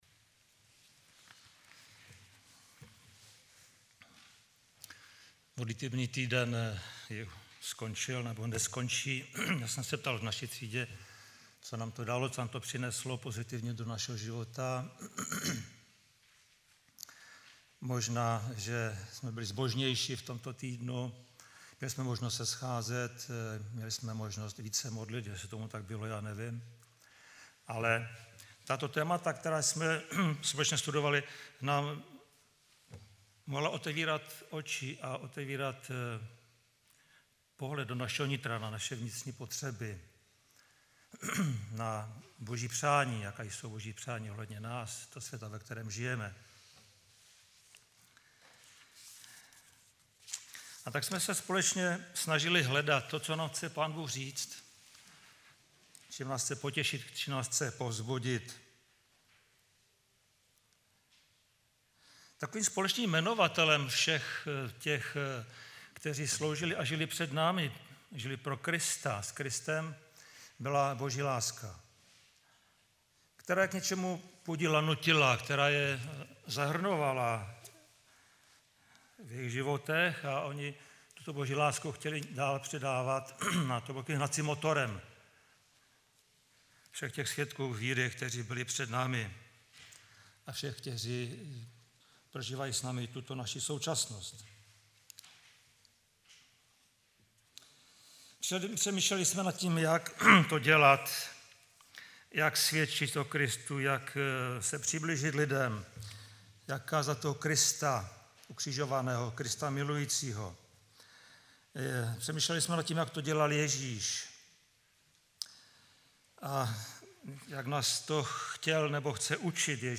Twitter Digg Facebook Delicious StumbleUpon Google Bookmarks LinkedIn Yahoo Bookmarks Technorati Favorites Tento příspěvek napsal admin , 13.11.2016 v 11:33 do rubriky Kázání .